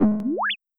UI_CredibilityIncrease.wav